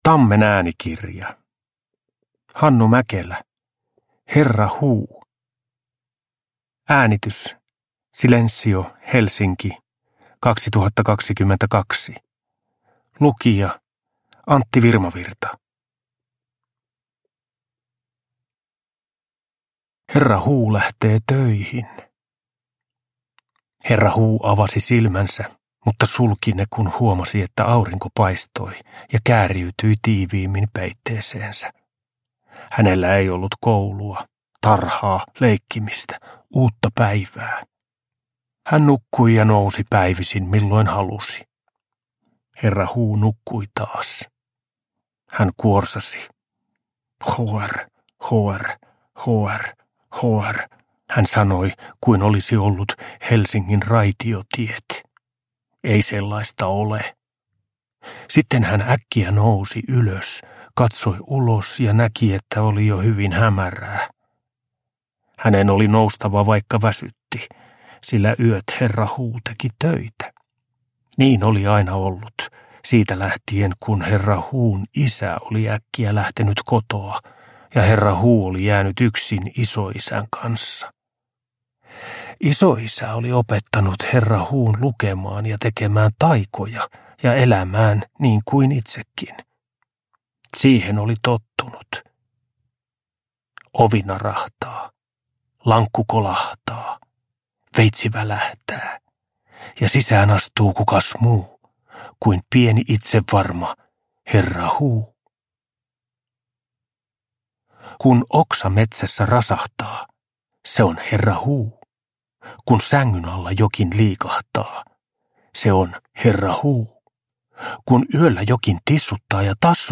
Herra Huu – Ljudbok